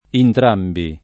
entrambi [entr#mbi] (antiq. entrambo [entr#mbo]) pron.; f. ‑be (non -bi) — ant. intrambi [